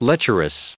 1651_lecherous.ogg